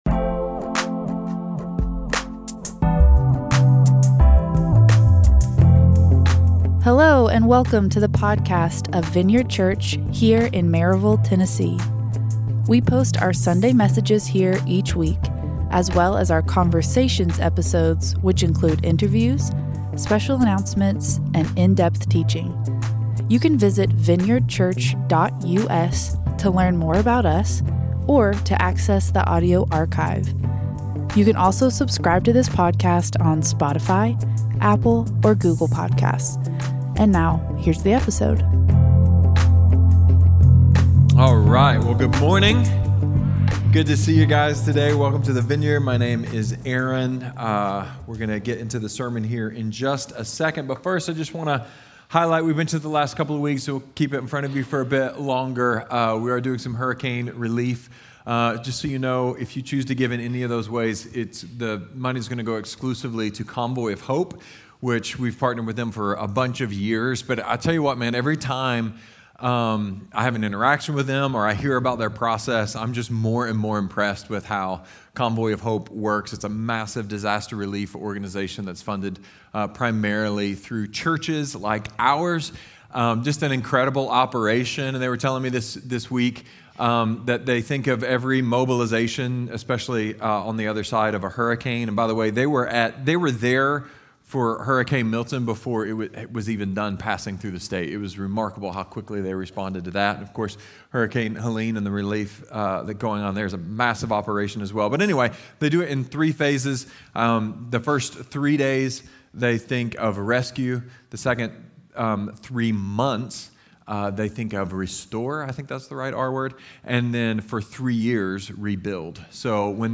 A message from the series "Trellis (October 2024)."